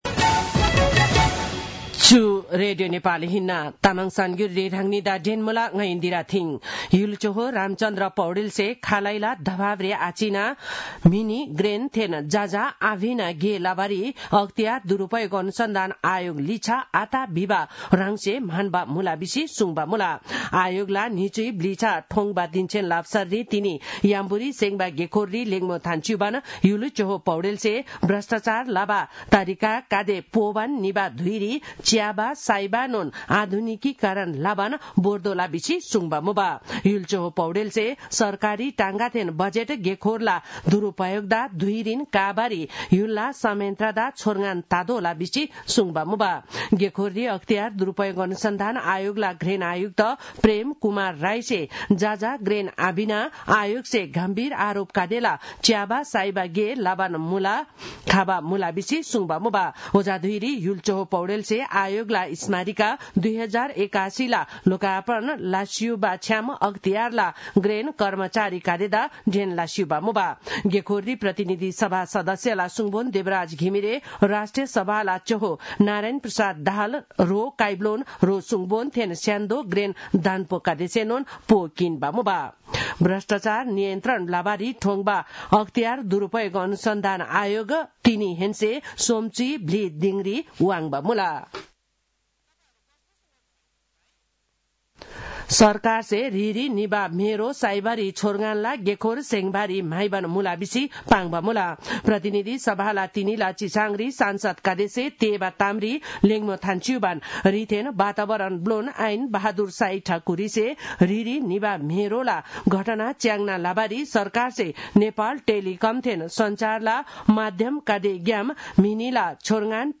तामाङ भाषाको समाचार : २९ माघ , २०८१
Tamang-news-10-28.mp3